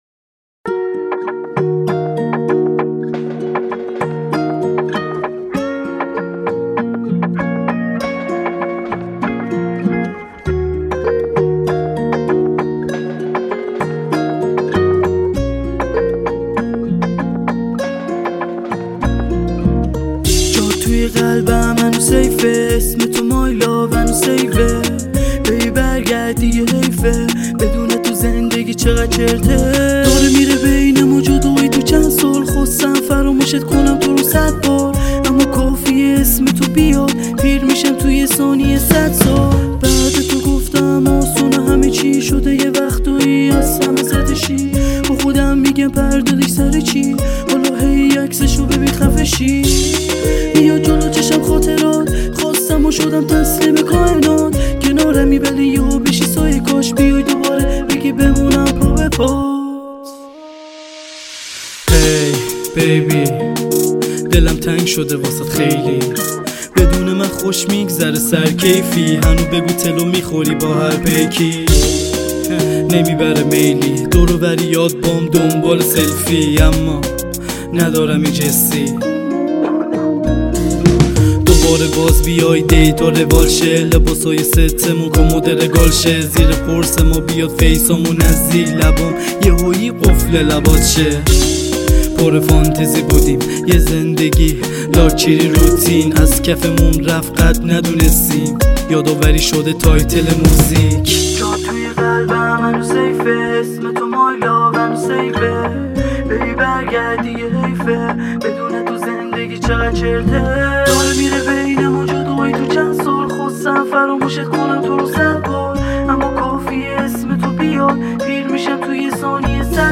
Persian Music